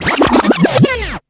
Backspin